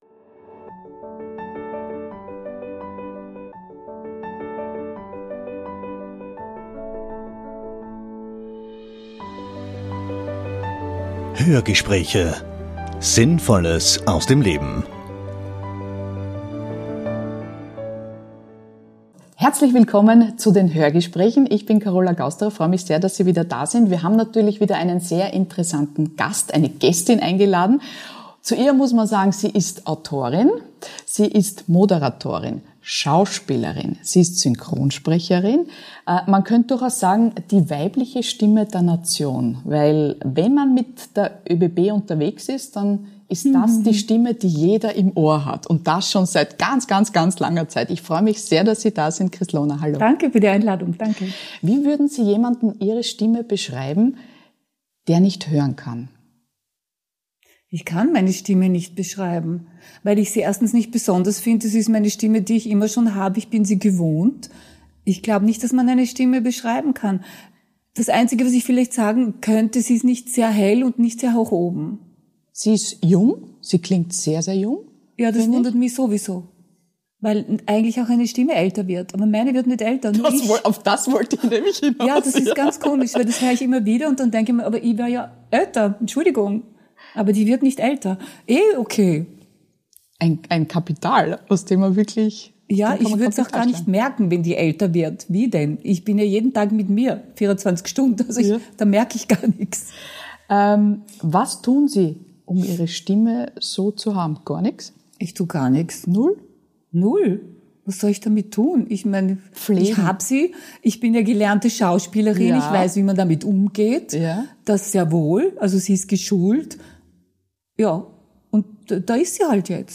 Die quirlige und umtriebige Autorin, Schauspielerin, Synchronsprecherin und Moderatorin spricht im neuen Hörgespräch über ihre derzeitigen Projekte, zahlreiche karitative Tätigkeiten und ihre beeindruckende Lebenserfahrung.